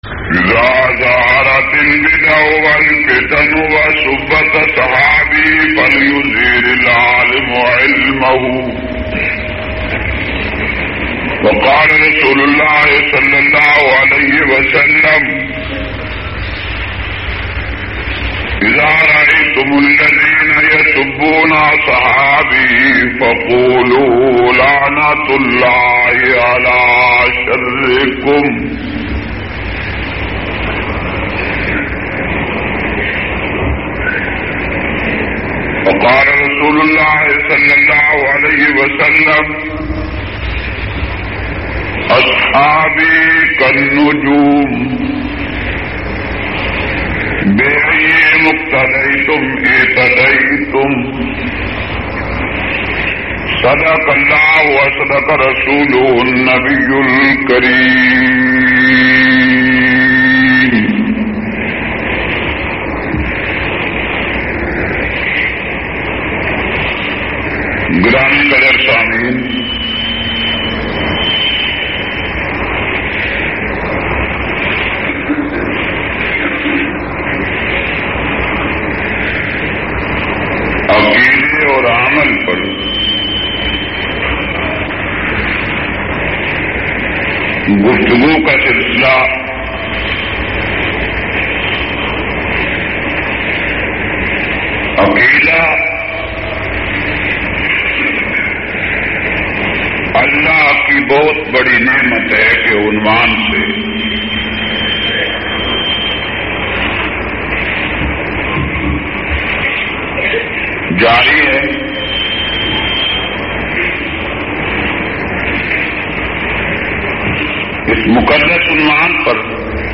262- Masla Hazir Nazir aur Waqia Mairaj Jumma Khutba Jhang.mp3